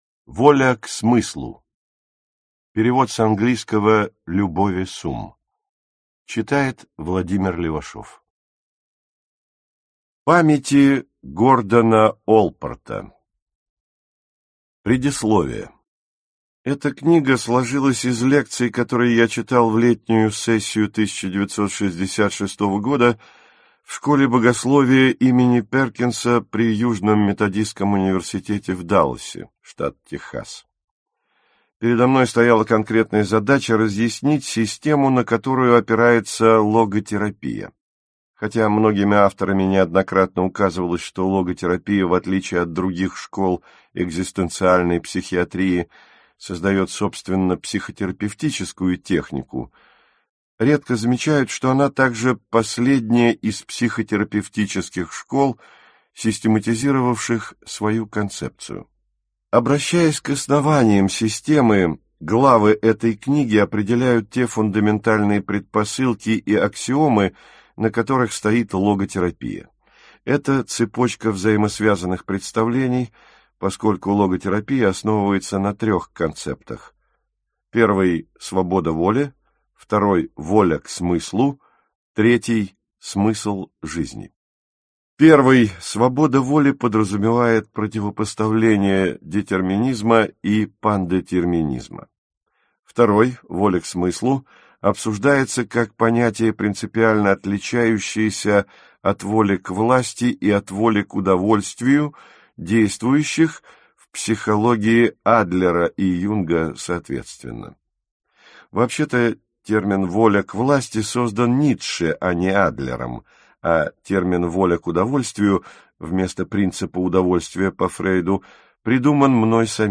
Аудиокнига Воля к смыслу | Библиотека аудиокниг